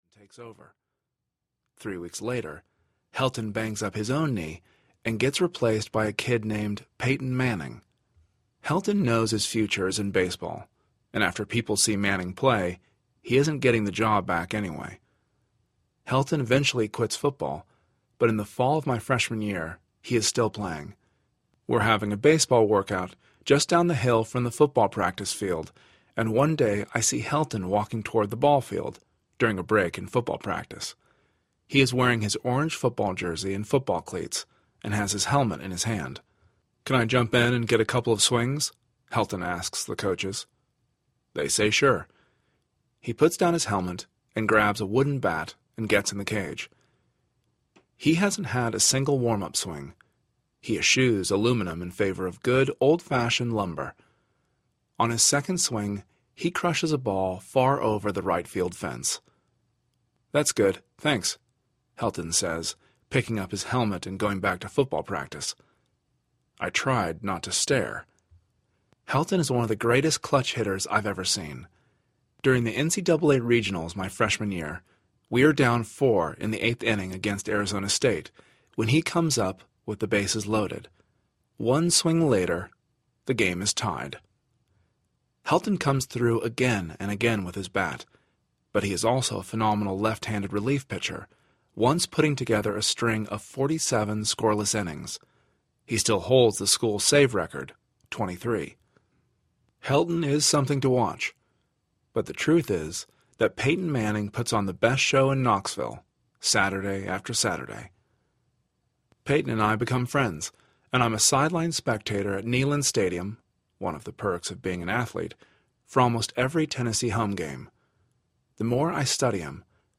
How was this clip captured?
9.5 Hrs. – Unabridged